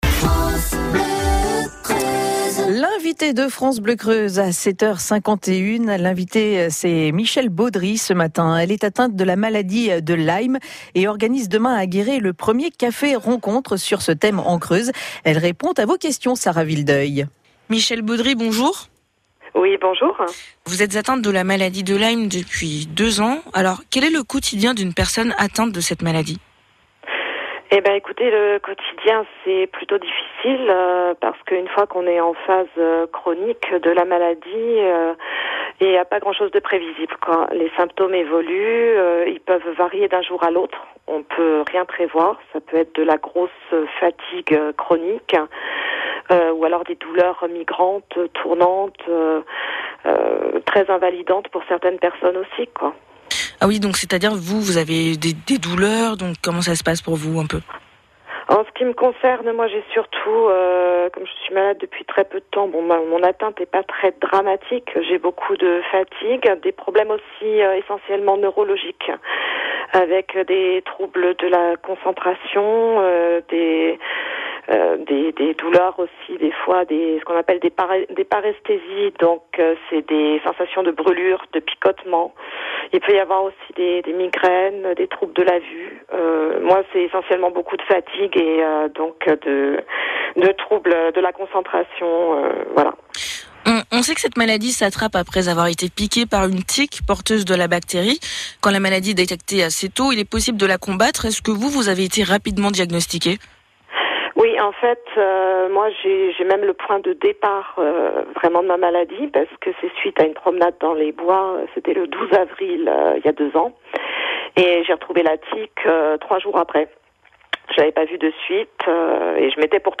Interview France Bleu Creuse